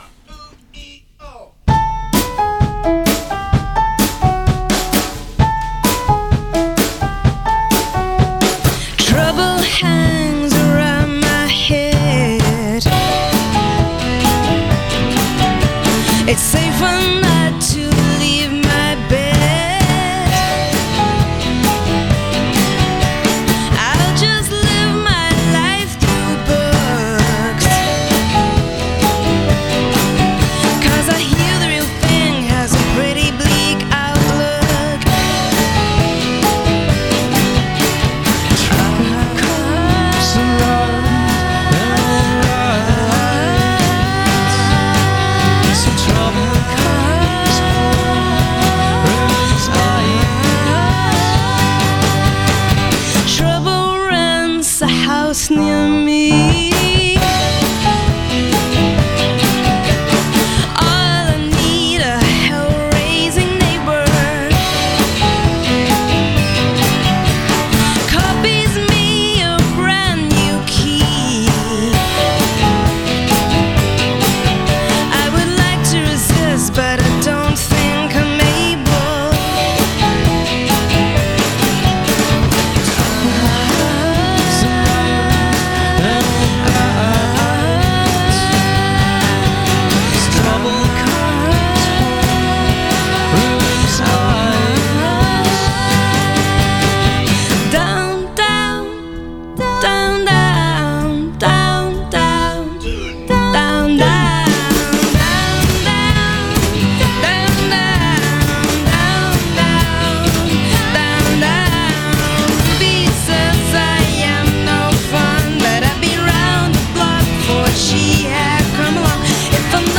Scottish-Canadian Supergroup
groundbreakers of a new Celtic genre